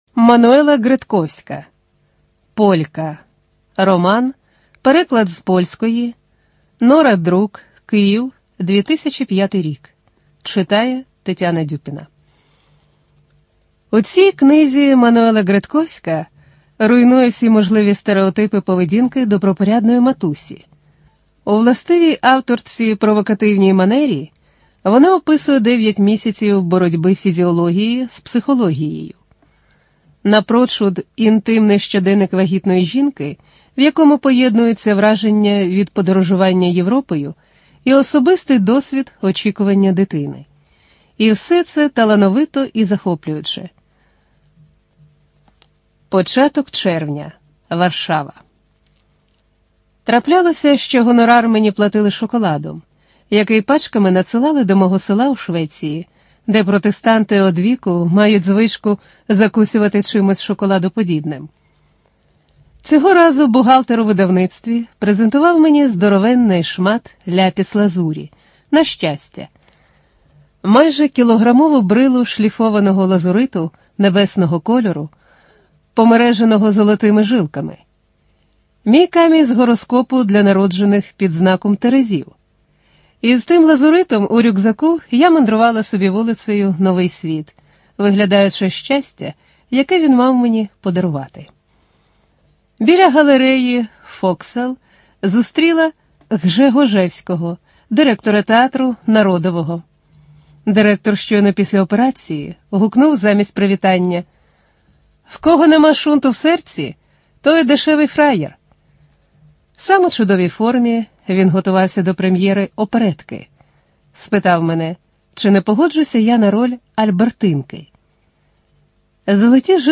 Студия звукозаписиРеспубликанский дом звукозаписи и печати УТОС
Бітрейт: 64 kbps, 44100 Hz, Mono